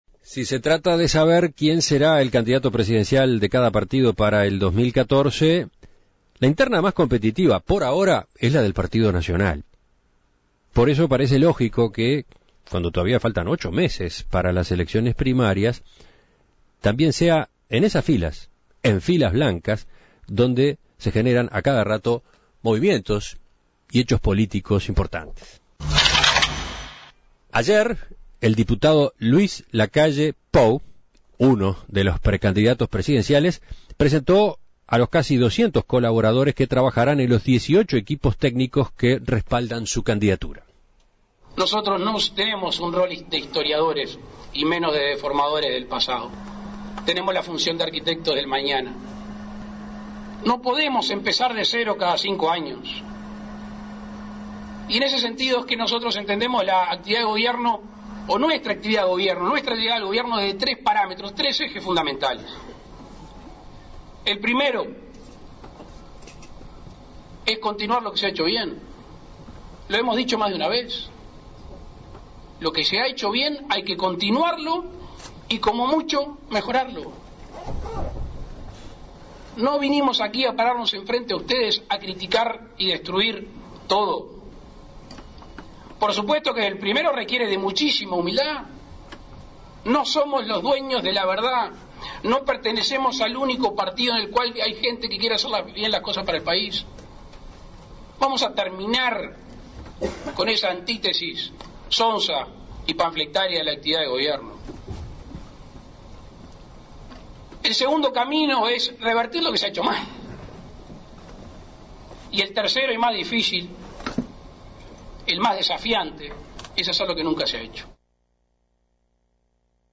El diputado nacionalista y precandidato presidencial Luis Lacalle Pou presentó ayer al equipo que elaborará la agenda de gobierno con la cual competirá en las elecciones primarias de junio de 2014. En diálogo con En Perspectiva, explicó que dicha agenda se basará por un lado en continuar y mejorar lo que a su juicio se ha hecho bien y por otro en cambiar lo que no funciona y encarar no se ha hecho.
Entrevistas